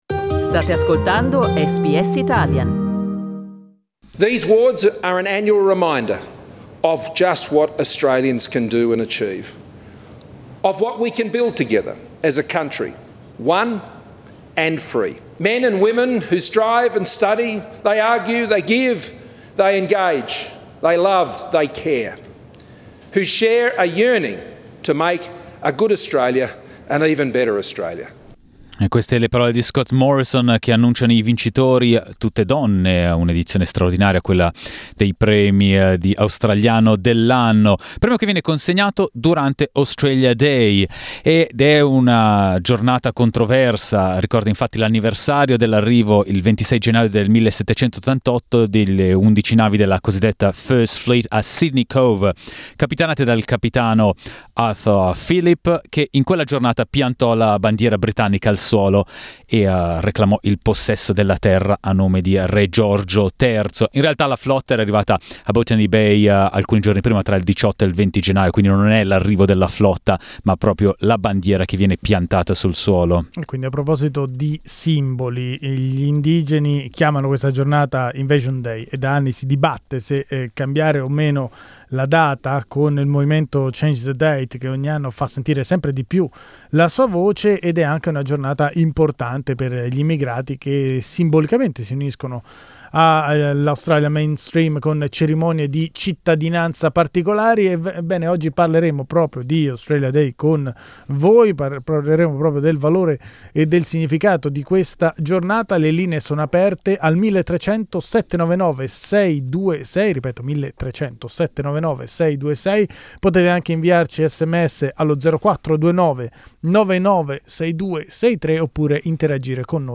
SBS Italian ha aperto le linee per parlare di questi due aspetti con gli ascoltatori.